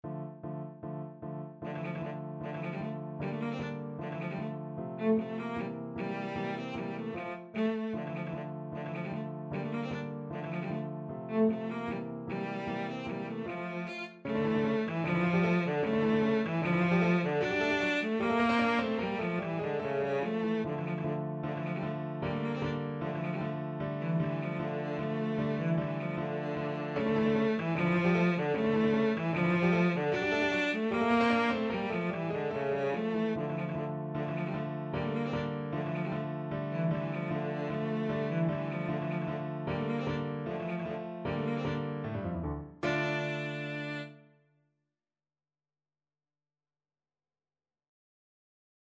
Classical Burgmuller, Friedrich Arabesque from 25 Progressive Pieces, Op.100 Cello version
Cello
D minor (Sounding Pitch) (View more D minor Music for Cello )
Allegro scherzando (=152) (View more music marked Allegro)
2/4 (View more 2/4 Music)
Classical (View more Classical Cello Music)